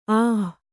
♪ āh